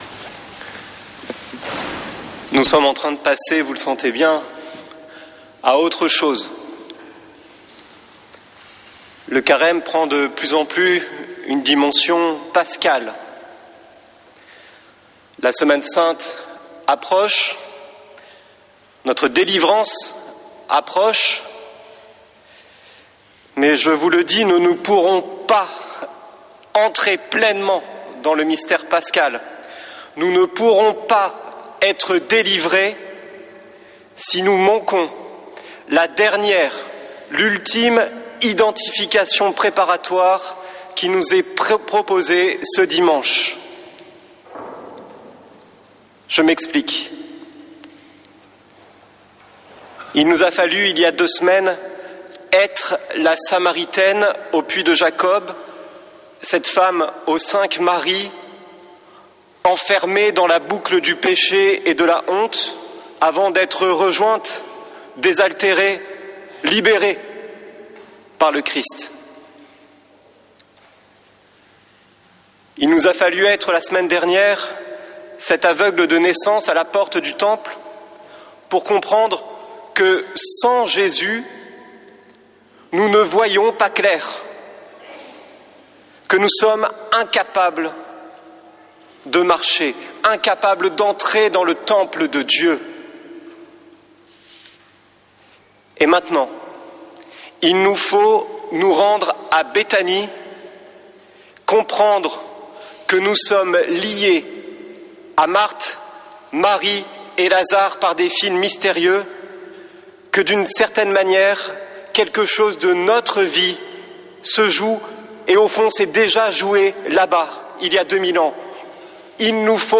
Messe depuis le couvent des Dominicains de Toulouse du 22 mars